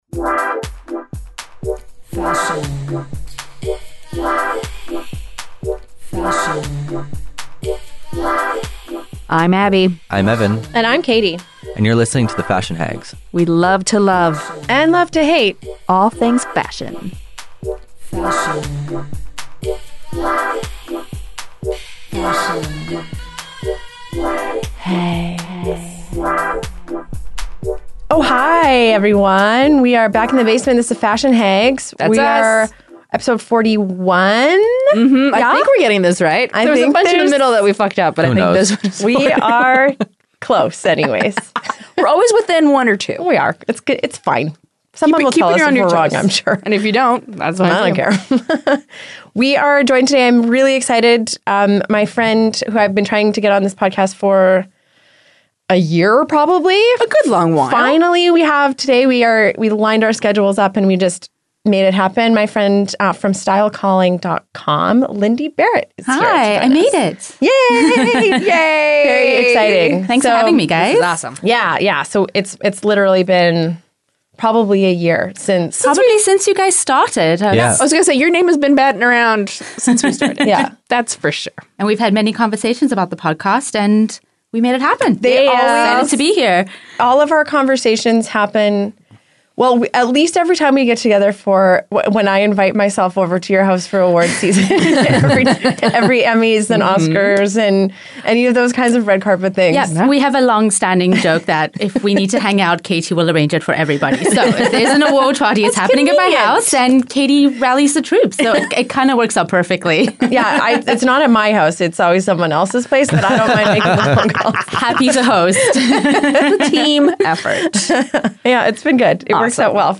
into the basement for a hot chat